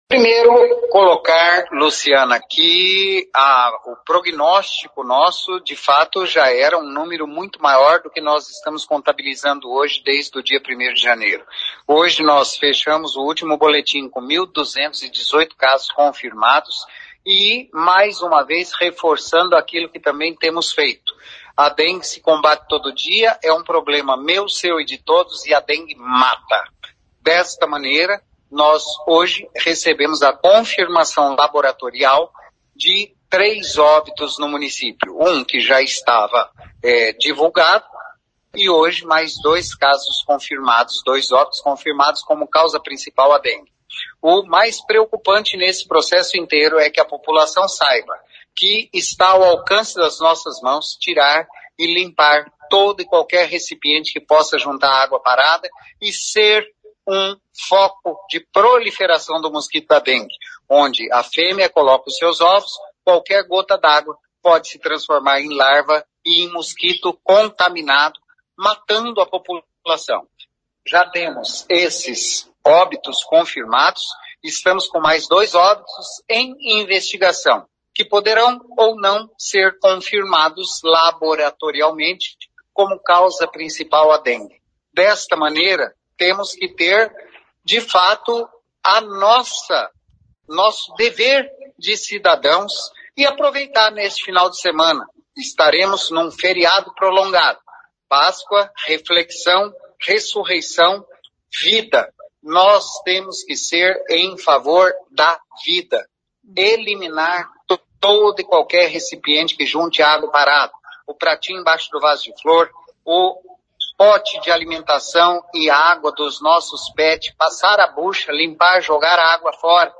Ouça o que diz o secretário de Saúde Antônio Carlos Nardi.